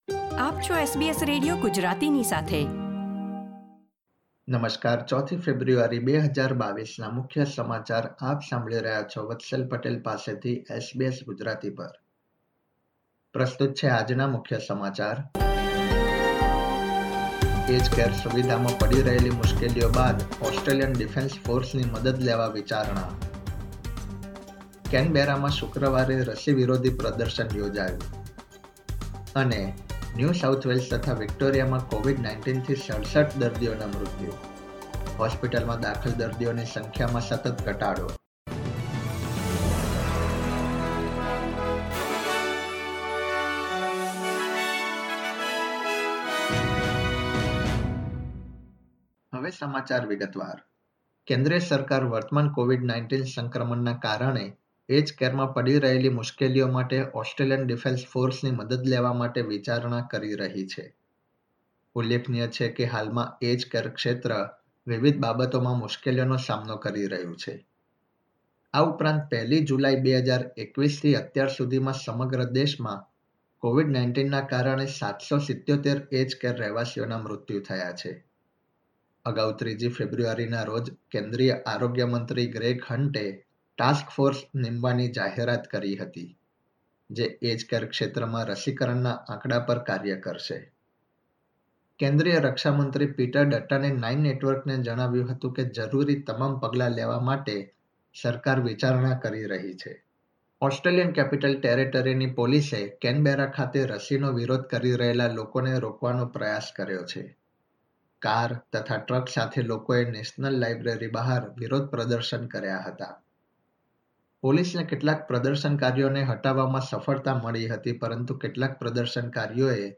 SBS Gujarati News Bulletin 4 February 2022